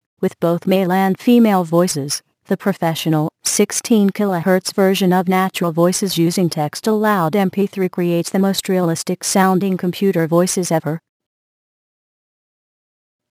Texte de d�monstration lu par Crystal (AT&T Natural Voices; distribu� sur le site de Nextup Technology; homme; anglais)